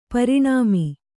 ♪ pariṇāmi